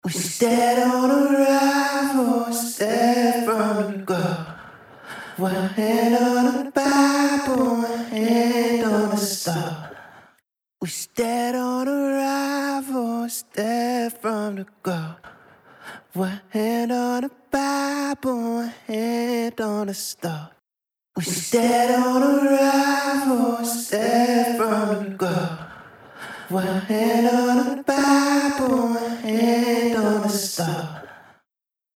Octavox | Vocals | Preset: Massive Detune
Octavox-Eventide-Male-Vocal-Massive-Detune.mp3